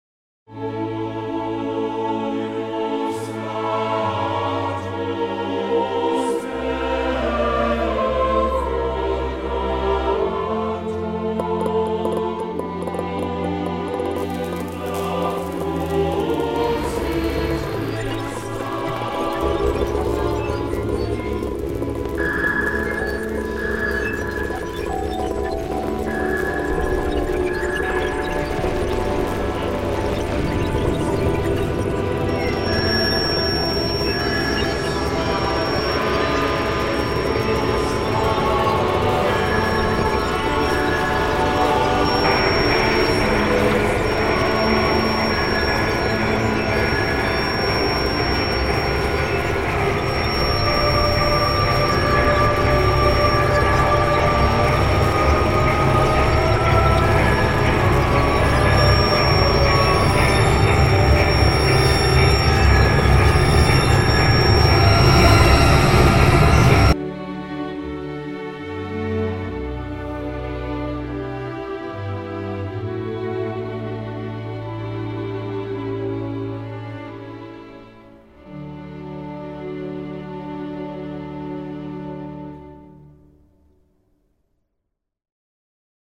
First, listen to the symphony of health (magnetic field of the earth),
then to the interference caused by “electro-smog”.
Lastly, you can detect the beautiful symphony sound again, picturing yourself lying on the mat.